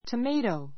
tomato 小 A1 təméitou ト メ イトウ ｜ təmɑ́ːtou ト マ ートウ （ ⦣ × ト マト ではない） 名詞 複 tomatoes təméitouz ト メ イトウ ズ トマト eat [slice] a tomato eat [ slice ] a tomato トマトを食べる[薄 うす く切る] We grow tomatoes in our garden.